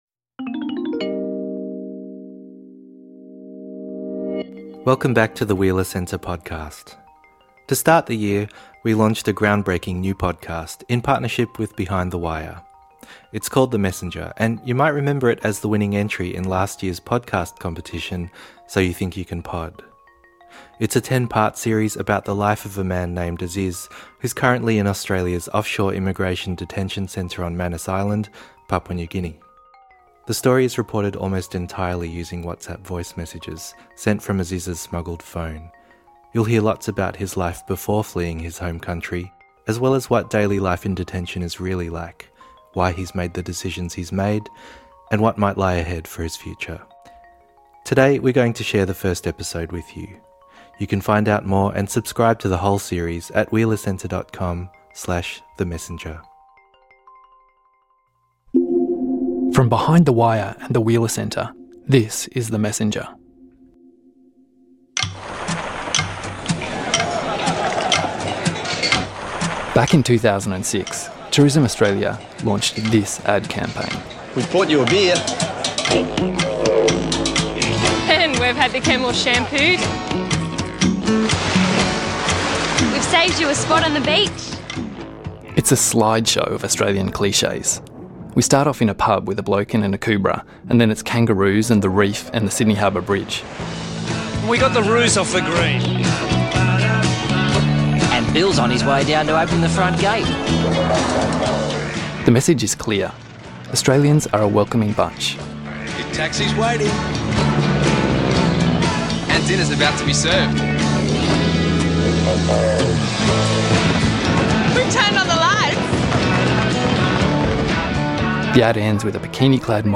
The Messenger brings you into the Australian immigration detention centre on Manus Island – and reveals, in intimate detail, one man's experience of what it's really like to flee tragedy and seek asylum by boat. It's reported almost entirely via WhatsApp voice messages sent from a smuggled phone.